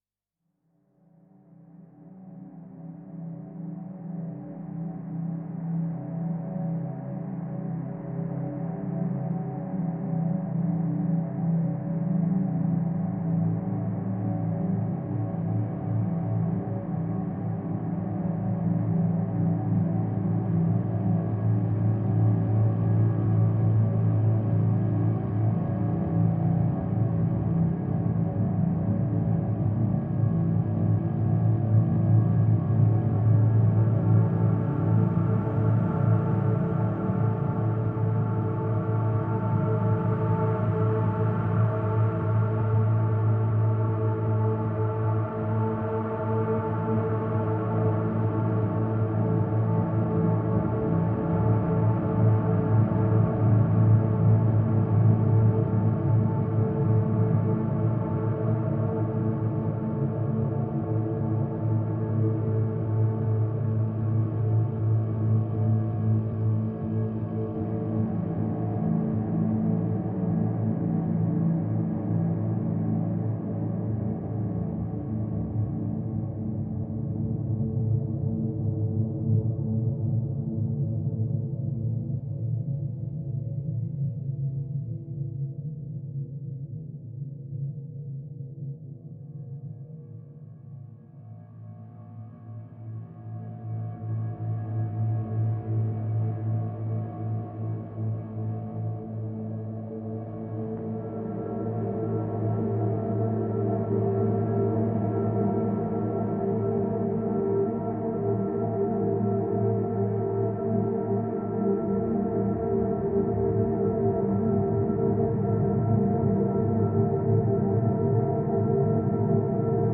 Dark, atmospheric pads filled with tension.